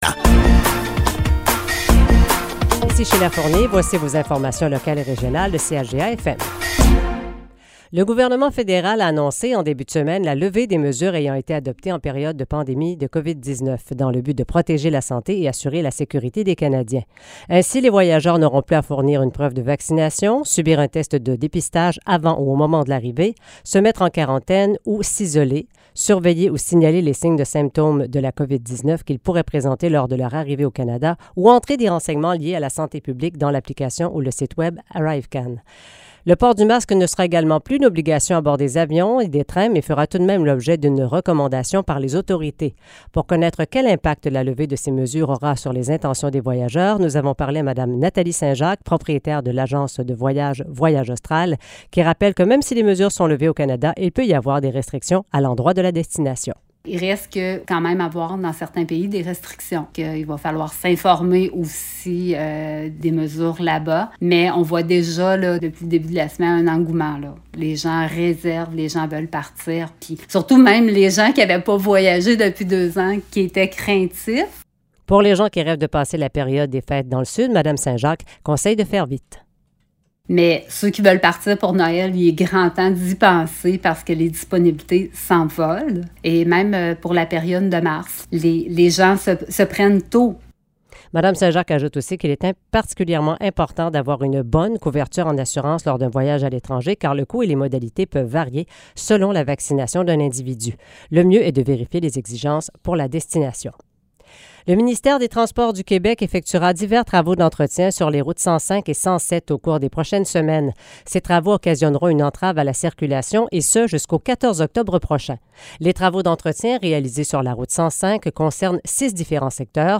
Nouvelles locales - 28 septembre 2022 - 12 h